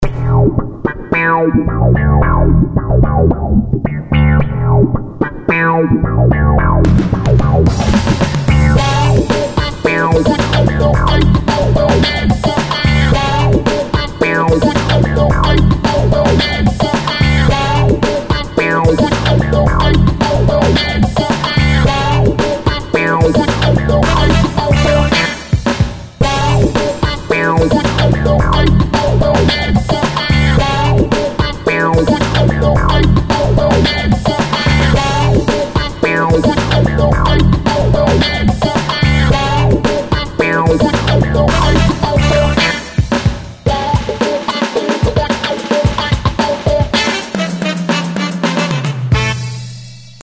Ñàì íèêàêèì ïðåìóäðîñòÿì çâóêîðåæèññóðû íå îáó÷àëñÿ, ñäåëàíî â Reason'e. Ïî÷åìó-òî íèêàê íå ìîãó îò íåãî îòâûêíóòü...
Ïîñòàðàëñÿ íà êàæäûé áàðàáàí ïîâåñèòü ïî ýêâàëàéçåðó, íî â ðèçîíå îí òîëüêî äâóõïîëîñíûé, òàê ÷òî îñîáî íå ïîâûïåíäðèâàåøüñÿ.